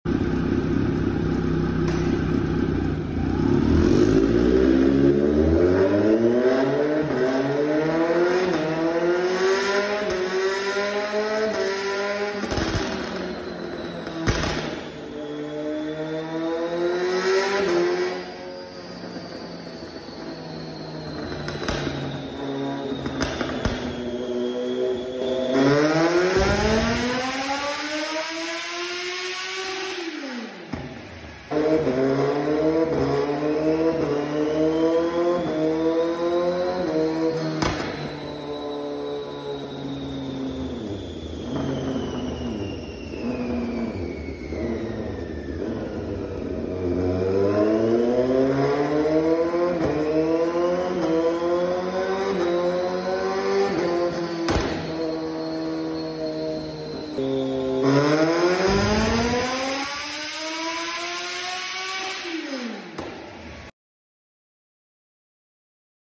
SCREAMING Z900 Fully SC PROJECT Sound Effects Free Download
SCREAMING Z900 fully SC- PROJECT EXHAUST🔥🗣🗣 STAGE 2 DYNO TUNED